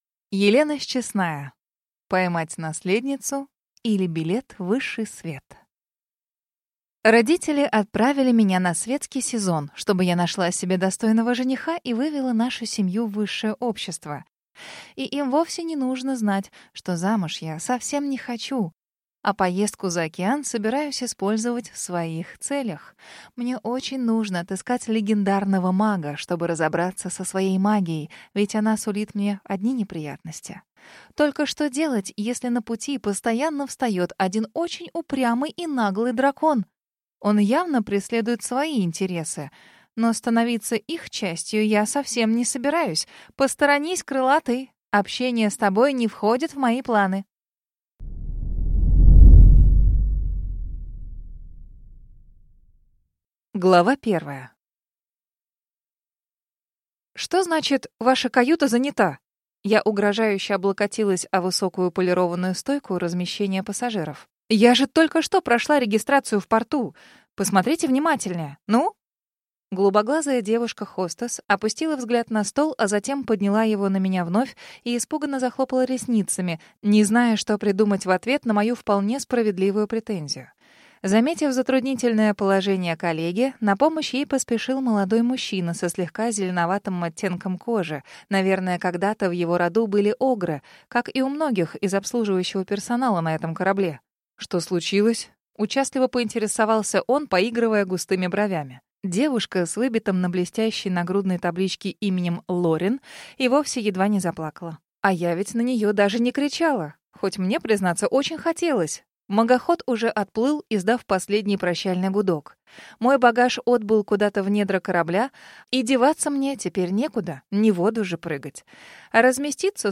Аудиокнига Поймать наследницу, или Билет в высший свет | Библиотека аудиокниг